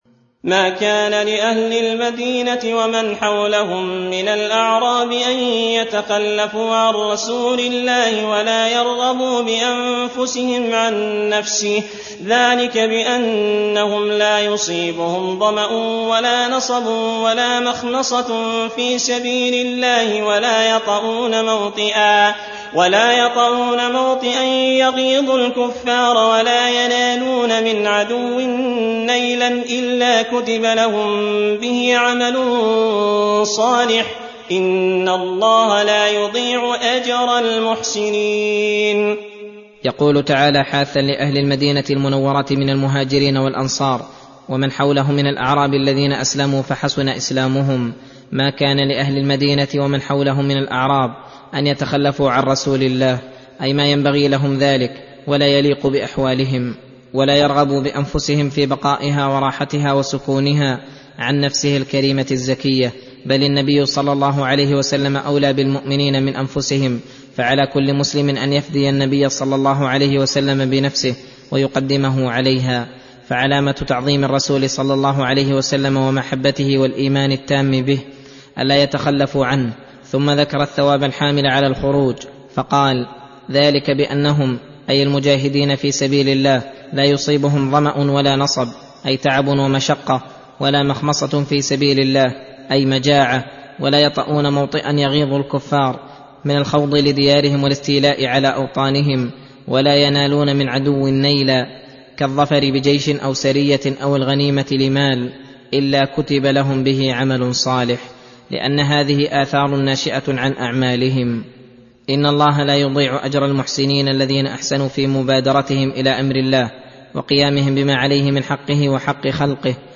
درس (24) : تفسير سورة التوبة (120-129)